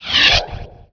1 channel
decloak.wav